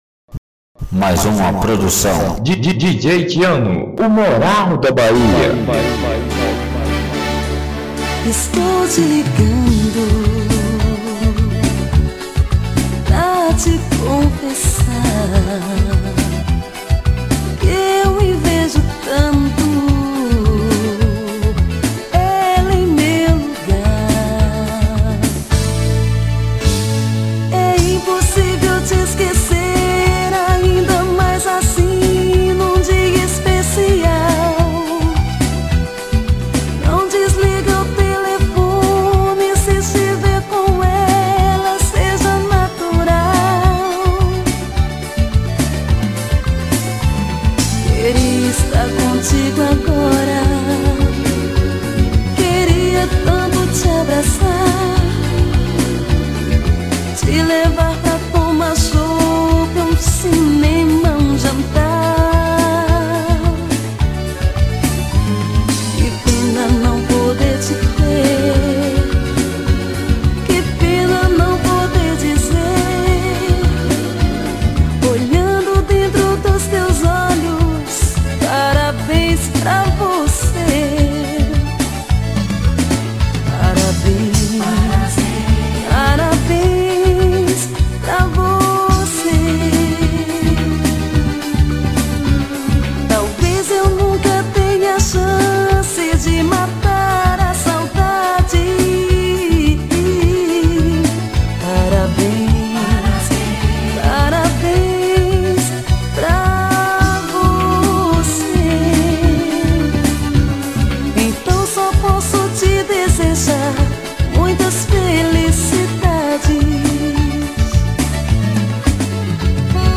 Forró romântico